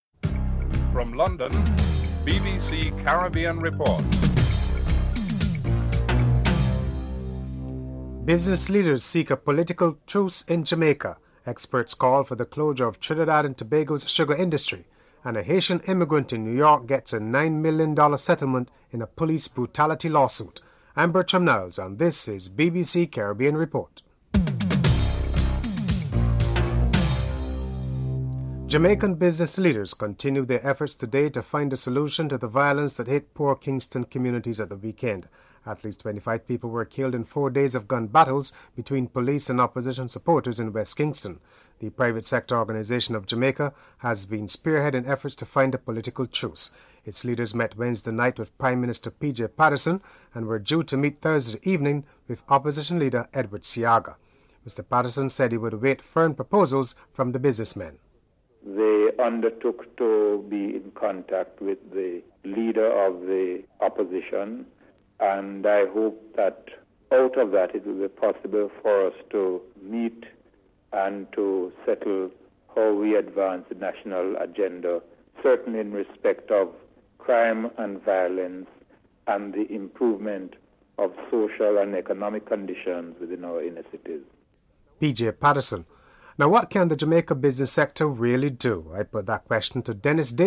1. Headlines (00:00-00:26)
Finance Minister Gerald Yetming is interviewed